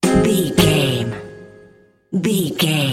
Aeolian/Minor
C#
percussion
flute
bass guitar
silly
circus
goofy
comical
cheerful
perky
Light hearted
sneaking around
quirky